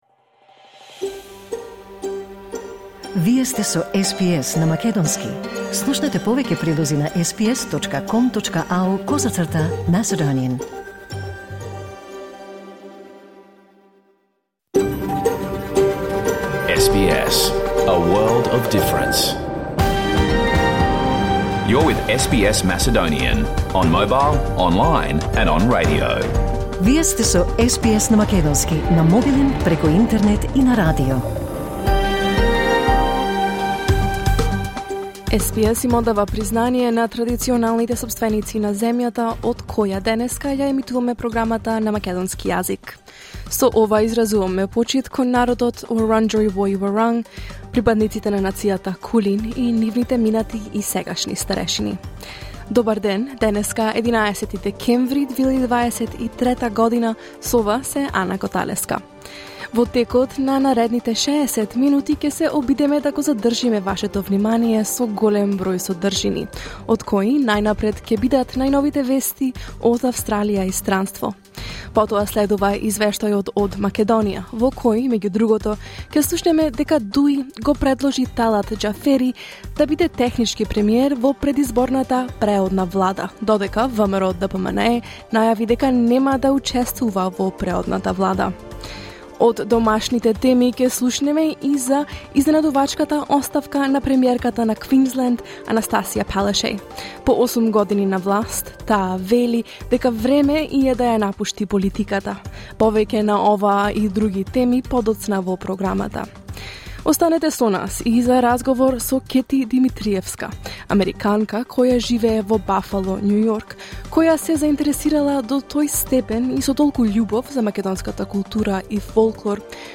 SBS Macedonian Program Live on Air 11 December 2023